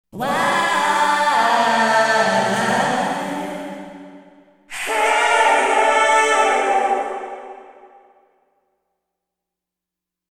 エフェクトの中でも使用頻度の高い リバーブ の比較です。
素材は 「DecaBuddy」 の時のコーラスを使いました。
SONAR FｘReverb
減衰音を聞きやすいように、かなり強めのリバーブをかけてみました。
リバーブをこれだけ強くかけると、どうしても音がコモってしまいがちです。